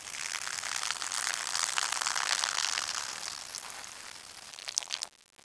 body_pee.wav